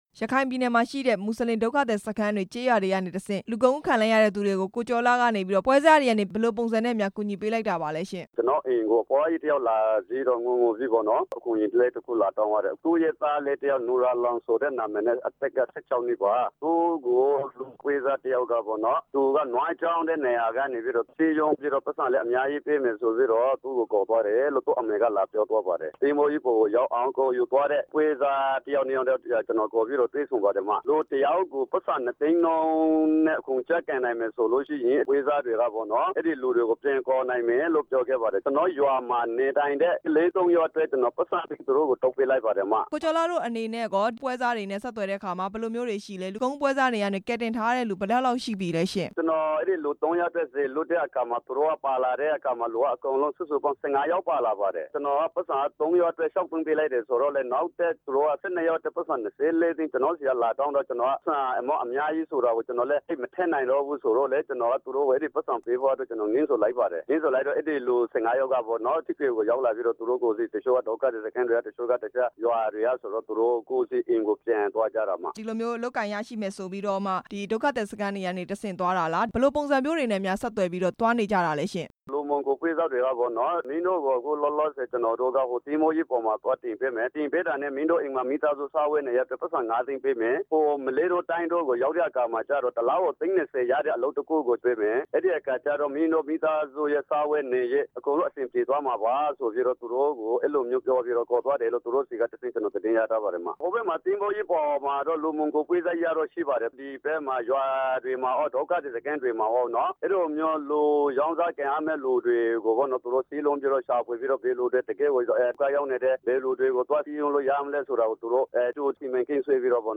လှေစီးဒုက္ခသည်တွေကို လူမှောင်ခိုပွဲစားတွေထံကနေ ပြန်လည်ဝယ်ယူသူနဲ့ မေးမြန်းချက်